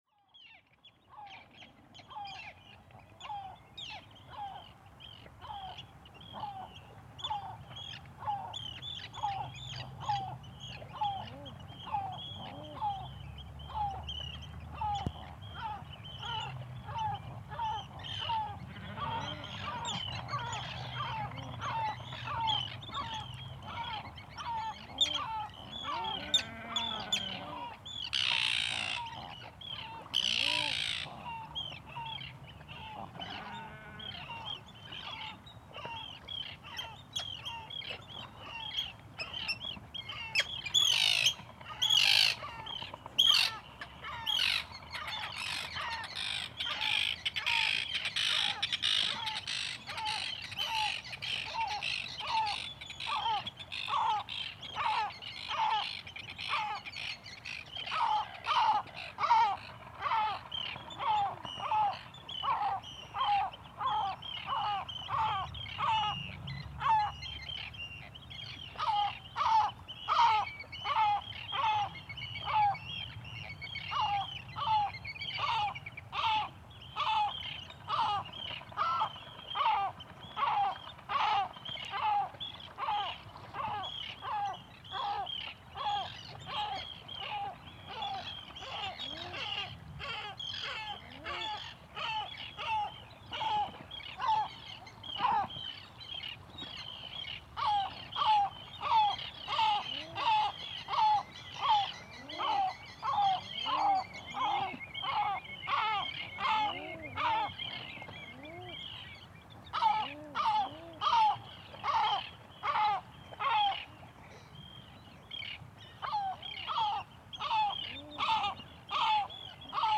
Akranes at Selströnd in summer solstice 2023. Part 2
Posted in Náttúra, tagged Arctic Puffin, Arctic tern, Æðarfugl, Common Eider, Common loon, Drangsnes, Eurasian Oystercatcher, Fjara, Golden Plover, Great Black backed Gull, Gull, IRT cross, Kría, Lóa, Lómur, Lesser Black Backed Gull, Lewitt LCT540s, Lundi, Red-throated Loon, Sílamáfur, Selströnd, Sound devices MixPre6, Steingrímsfjörður, Strandir, Strönd, Tjaldur, Whimbrel on 6.2.2025| Leave a Comment »
Just a minute before the recording started I had to move the microphones to a slightly more sheltered spot, because over the night the wind started to increase which unfortunately can still be heard in the recording. The recording is „unprocessed“. Recorded at +50dB gain, normalized up to -7dB by adding +20dB in post, fade in and out and then converted to mp3. The recording starts exactly at 4:00 in the morning on June 22, 2023 and ends just over half an hour later. After a high tide earlier in the night, the tide was already starting to recede.